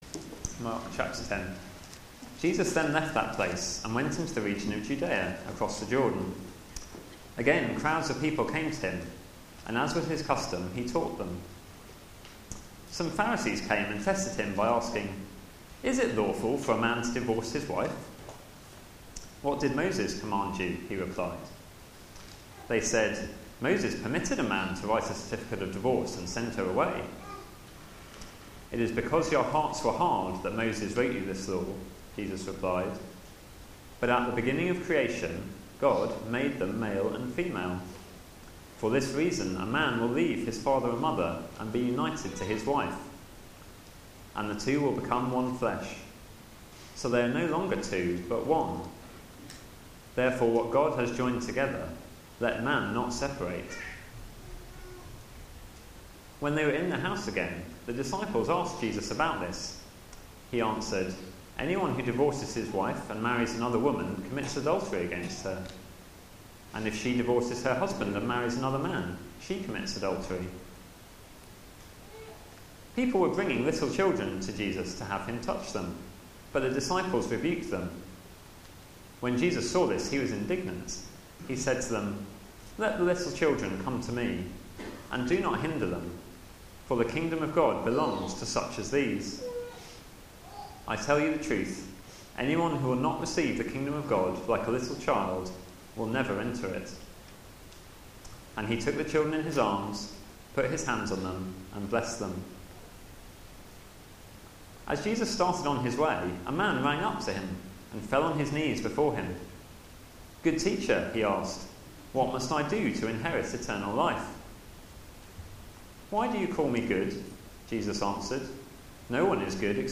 Media for Sunday Service on Sun 15th Sep 2013 11:00
Sermon